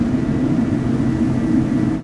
techage_turbine.ogg